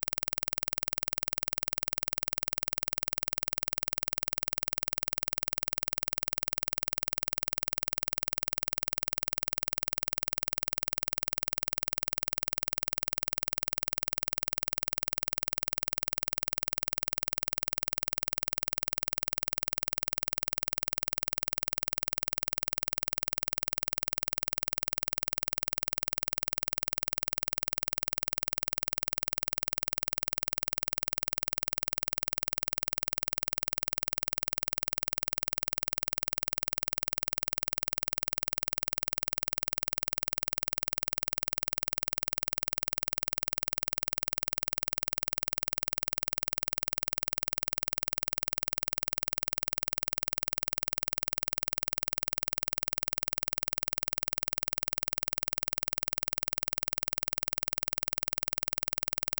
Click track
Test signal to simulate vocal fry:
Plays on right channel only:
impulse-train-20-right.flac